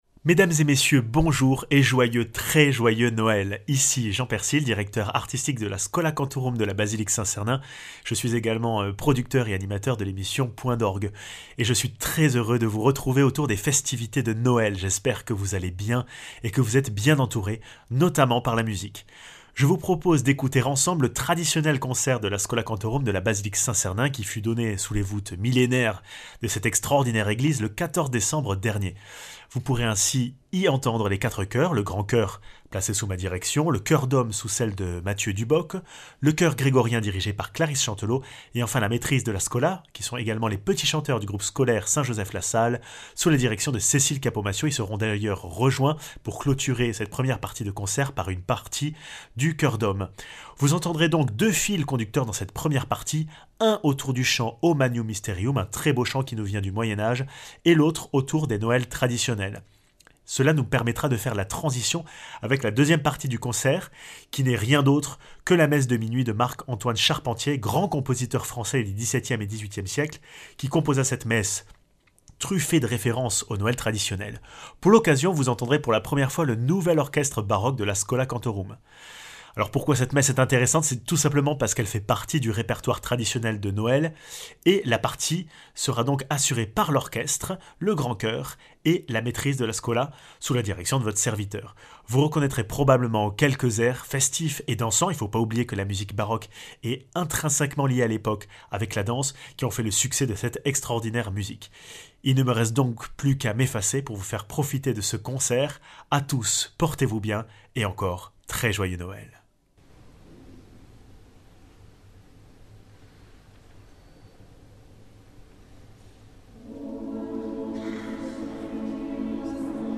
Retrouvez les quatre chœurs de la schola cantorum ainsi que son nouvel orchestre baroque avec un programme féérique sur la nuit de Noël. A l’honneur cette année : le chant O magnum mysterium à travers les siècles, et les Noëls français populaires. En deuxième partie de concert, vous aurez le plaisir d’entre l’un des plus grands morceaux de la musique baroque française : la Messe de minuit de Marc-Antoine Charpentier